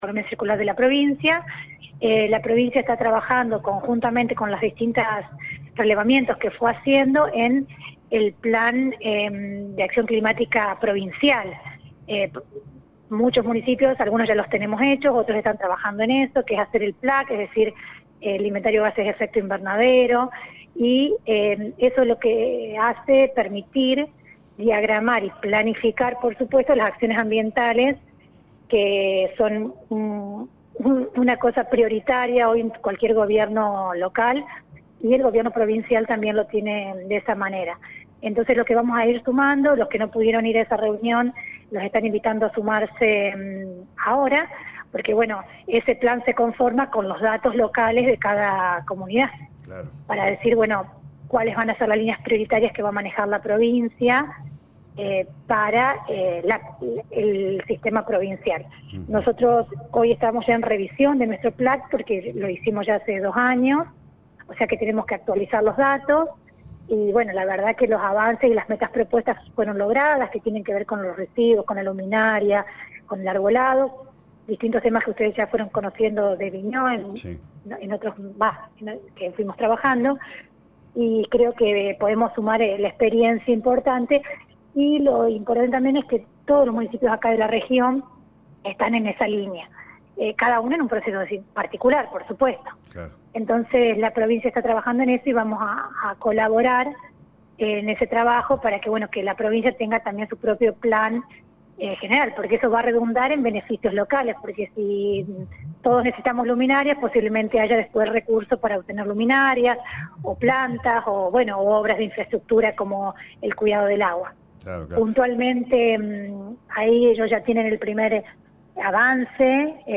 En diálogo con LA RADIO 102.9 FM la intendente de Colonia Vignaud Lic. Evangelina Vigna destacó que forma parte del Foro Intermunicipal y Comunal para enfrentar el cambio climático.